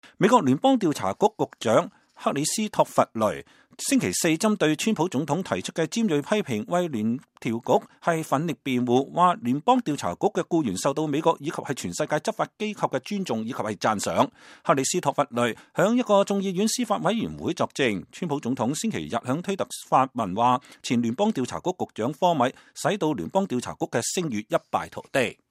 2017年12月7日，聯邦調查局局長克里斯托弗·雷在眾議院司法委員會宣誓作證。